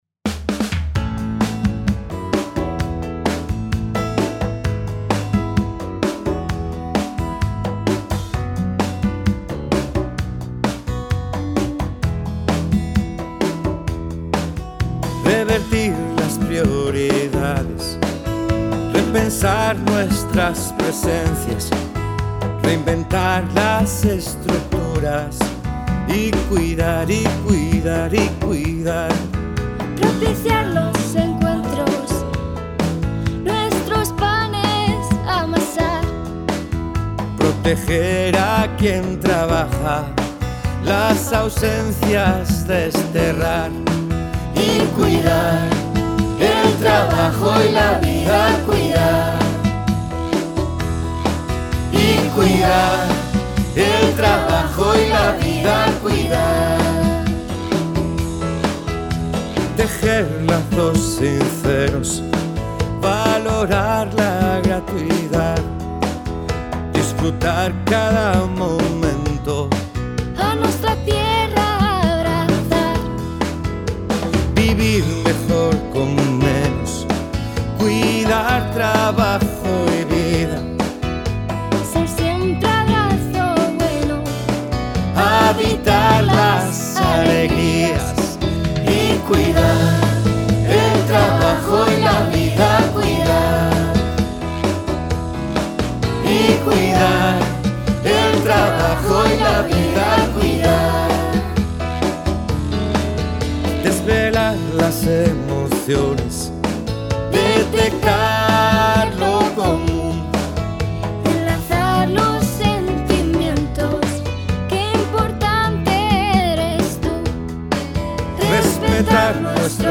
Canción de la campaña.
voz principal y coros.
piano, guitarras, bajo, batería, congas, percusiones y programaciones